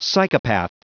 Prononciation du mot psychopath en anglais (fichier audio)
Prononciation du mot : psychopath